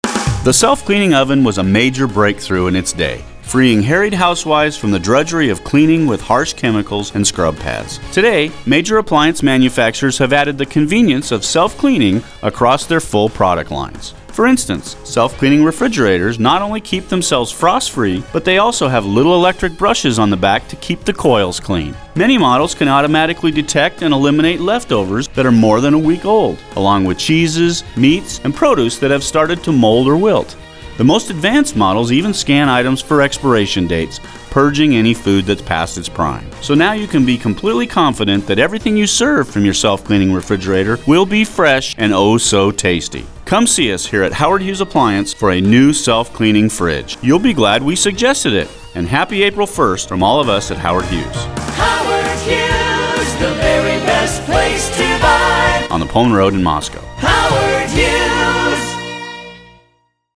This is one of eight different commercials running heavily all day April 1st on all six commercial radio stations in the market.
Though the advertiser’s delivery and jingle sound the same as always, the copy is…a little different.